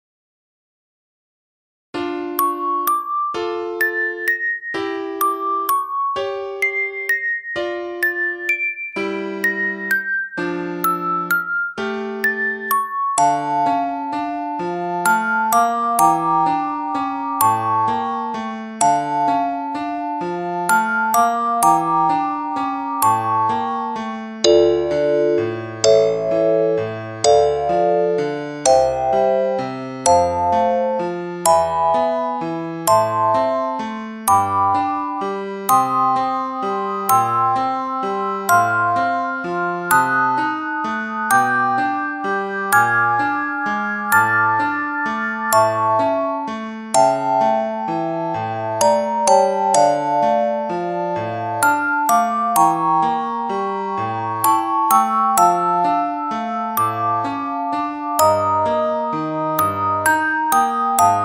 Feel the rhythm with every bounce.